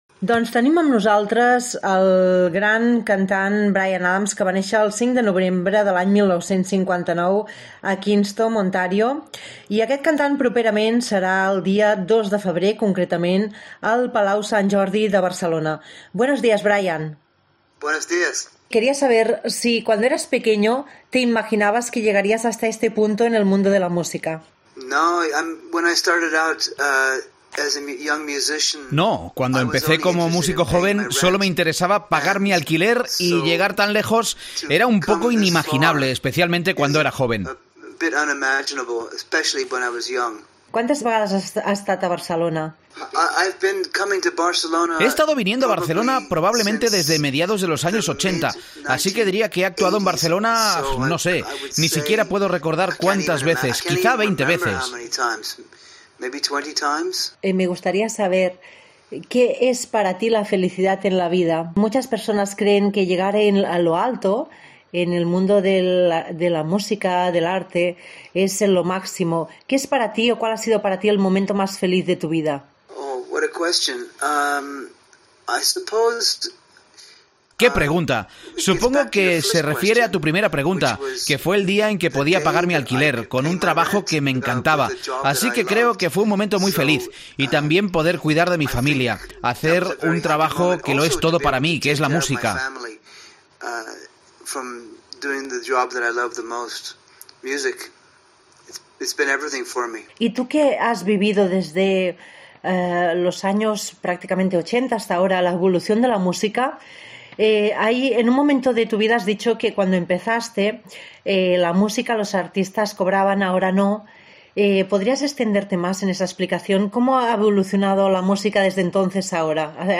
AUDIO: Entrevista Bryan Adams